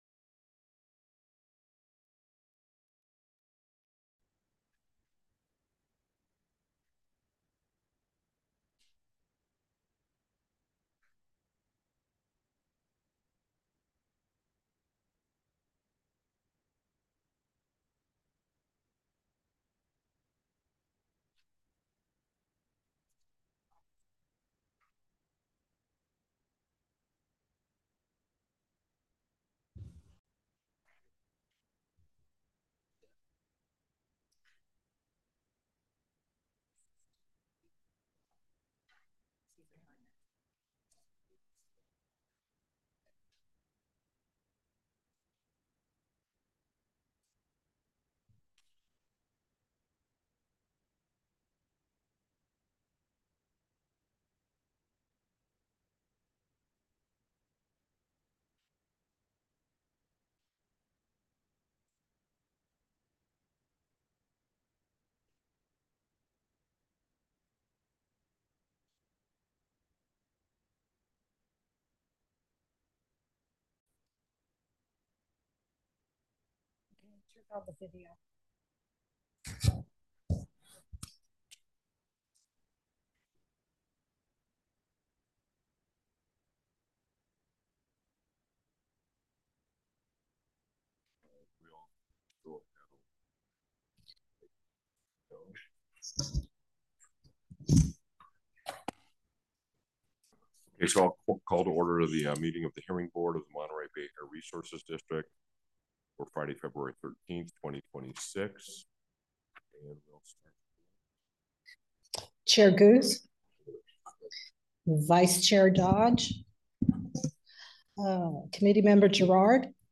Hearing Board Meeting